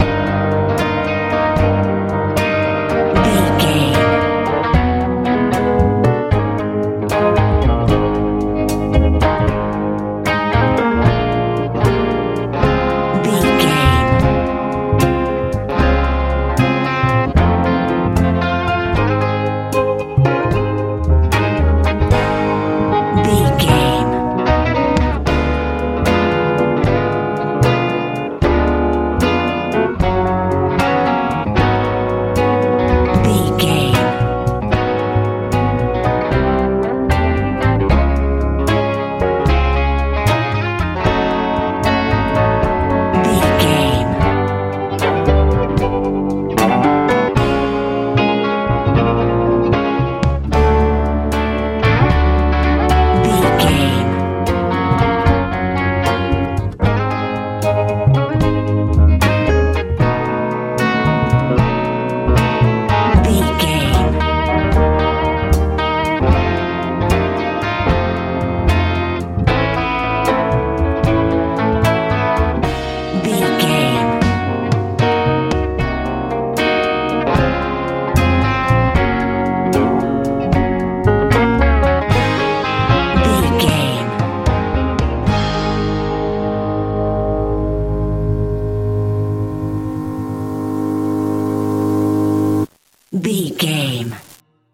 country rock feel
Ionian/Major
E♭
bright
electric guitar
piano
bass guitar
drums
soft
smooth